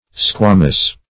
squamose - definition of squamose - synonyms, pronunciation, spelling from Free Dictionary
Squamose \Squa*mose"\ (skw[.a]*m[=o]s" or skw[=a]"m[=o]s`),